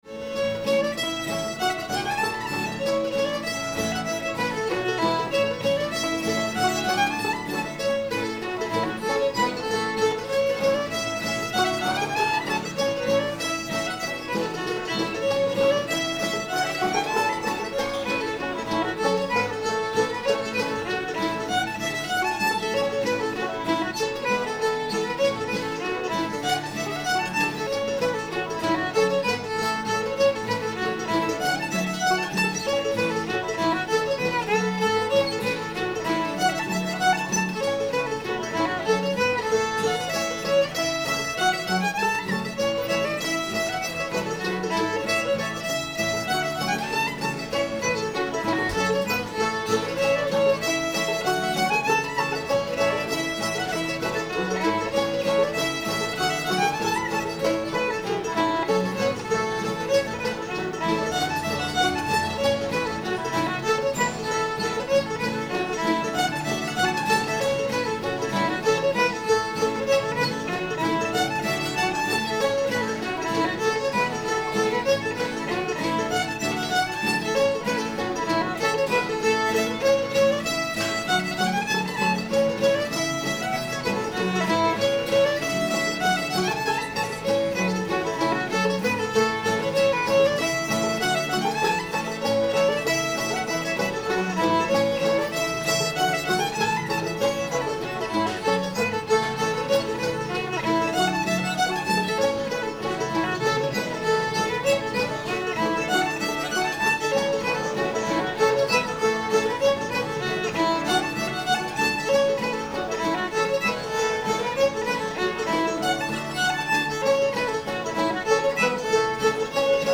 brushy run [A]